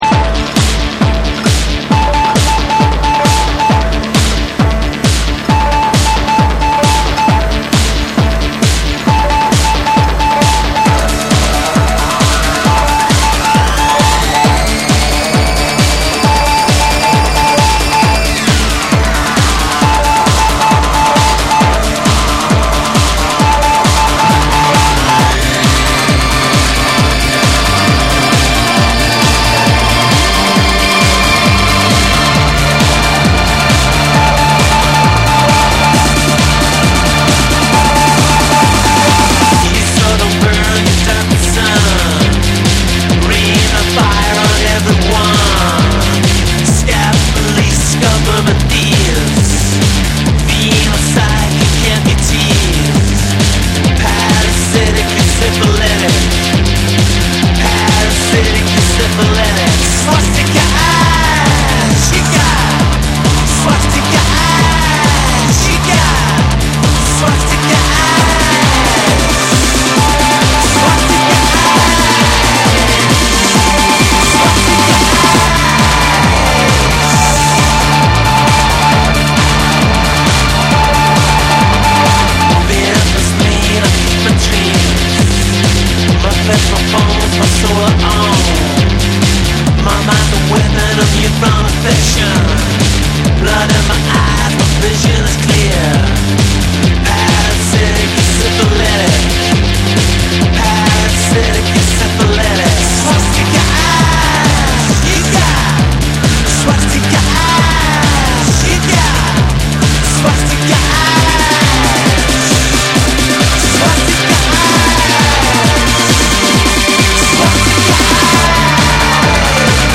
NEW WAVE & ROCK / TECHNO & HOUSE